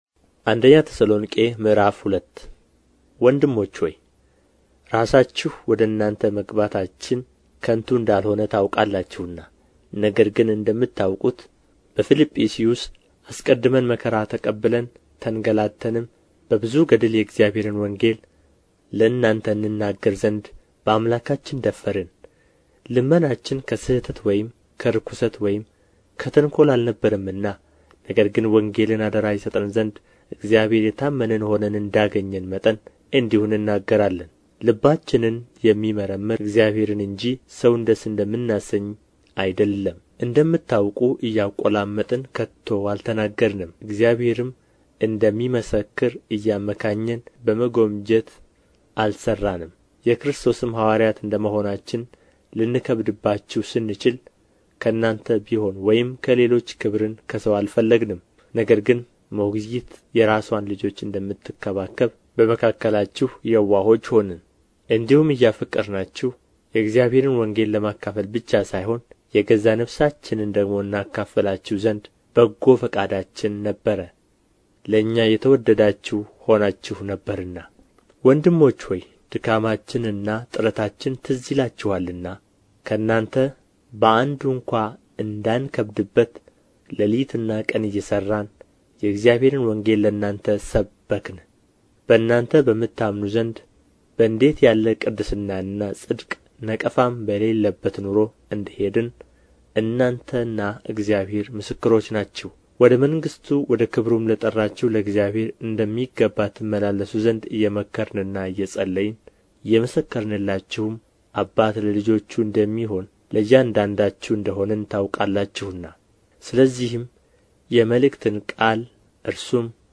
ምዕራፍ 2 ንባብ